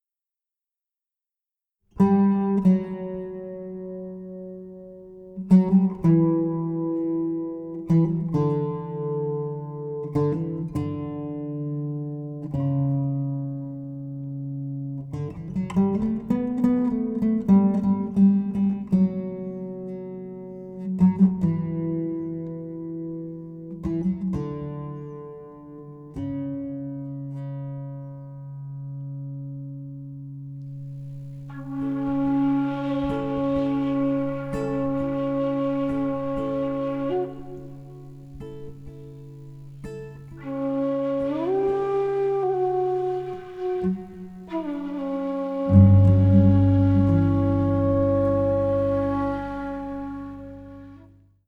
Flute, Alto flute, Bansuri flutes
16-string Classical guitar